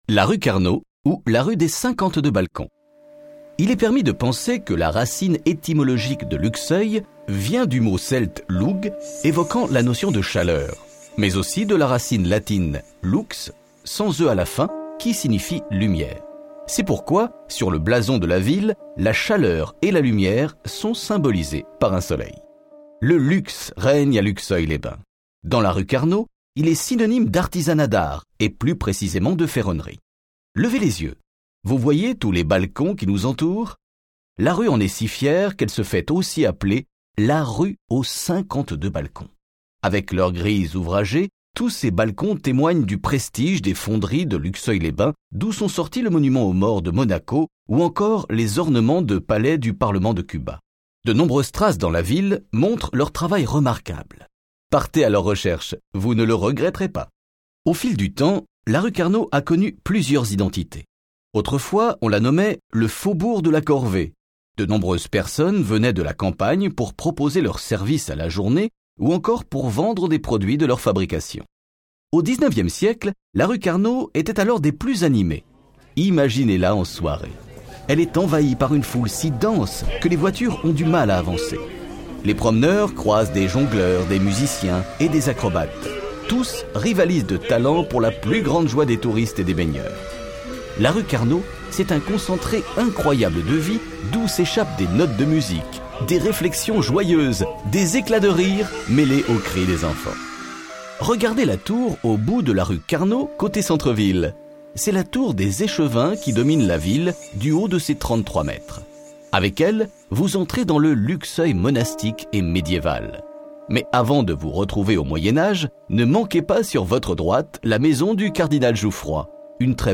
Balade audio – 02 La rue Carnot ou la rue aux 52 balcons
Explications audio